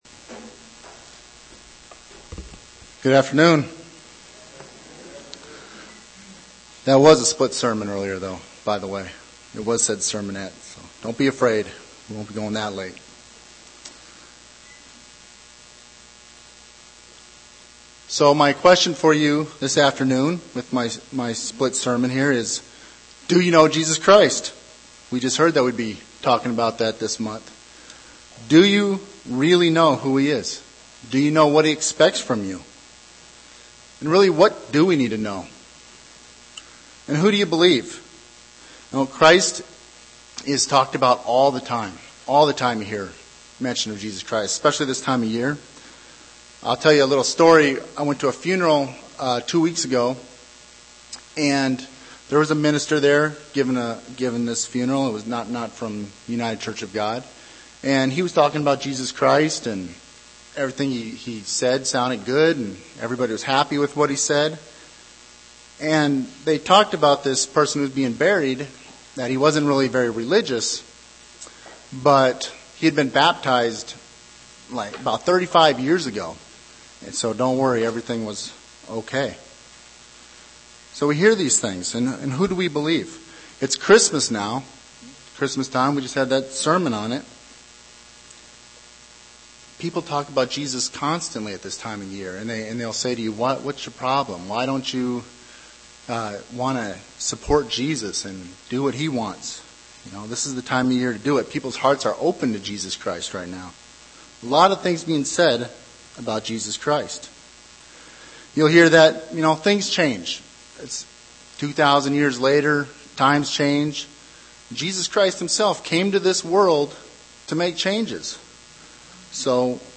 Given in Phoenix East, AZ
UCG Sermon Studying the bible?